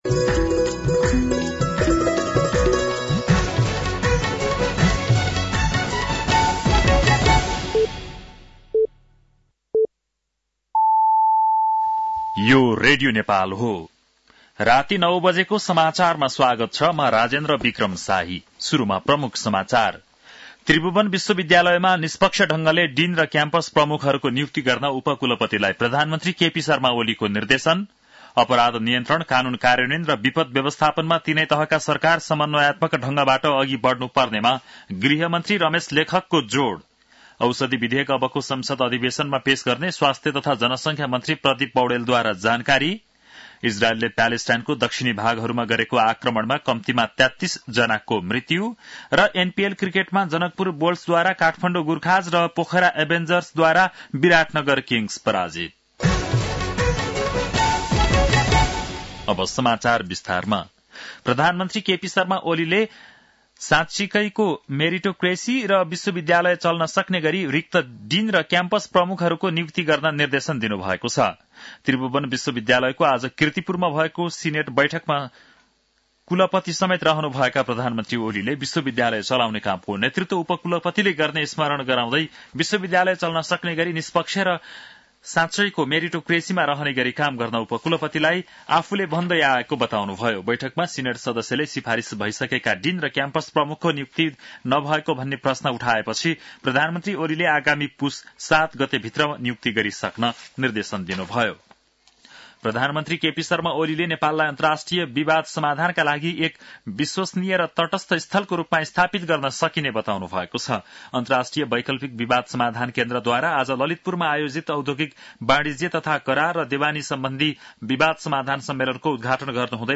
An online outlet of Nepal's national radio broadcaster
बेलुकी ९ बजेको नेपाली समाचार : २८ मंसिर , २०८१
9-PM-Nepali-NEWS-8-27.mp3